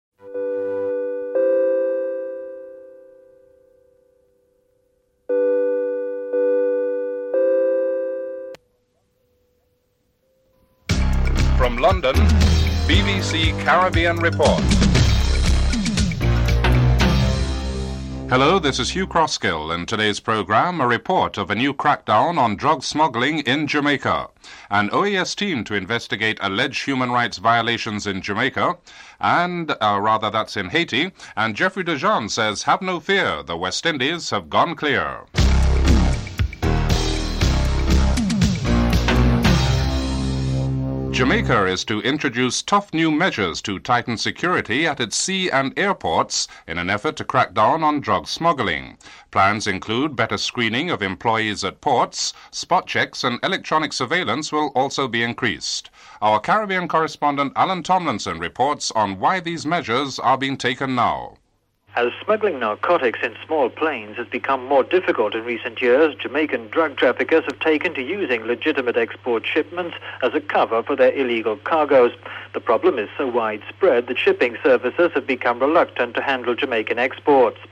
6. Report on headlines in various British newspapers: (08:52-11:02)